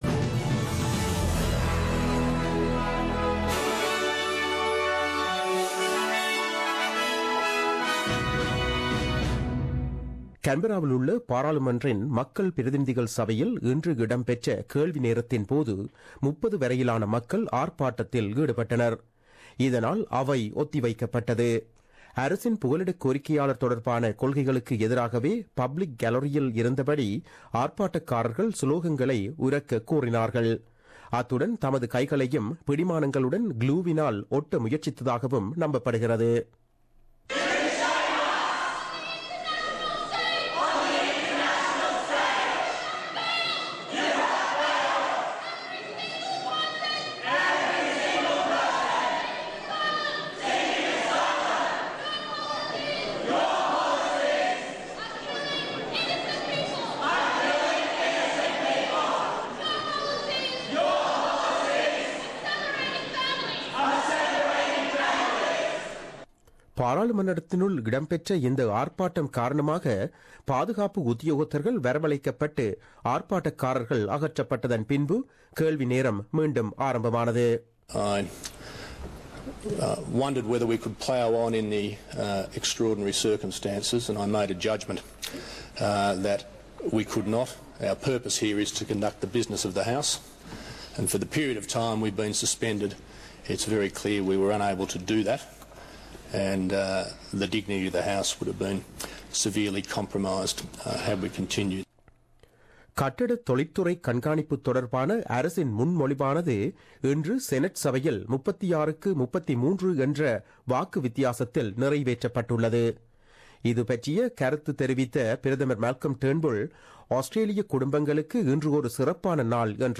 The news bulletin aired on 30 November 2016 at 8pm.